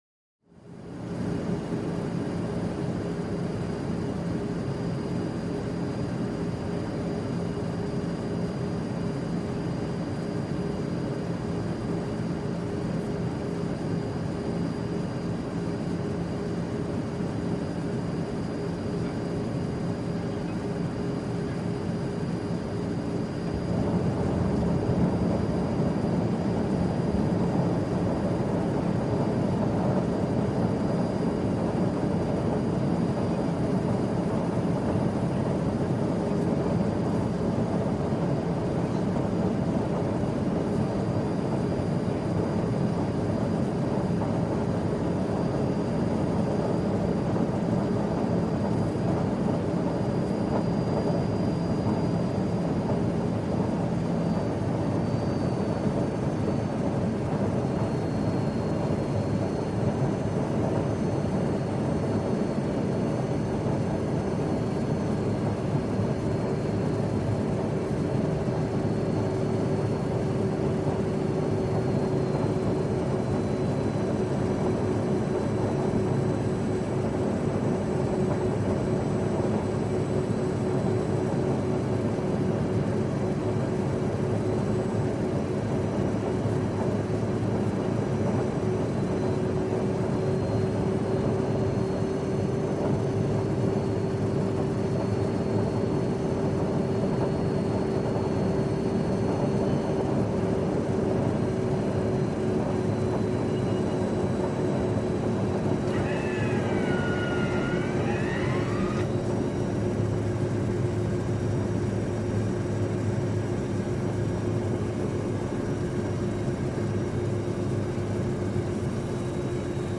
Звук аэроплана: снижается в полете, приземление
Аэропланы, авиация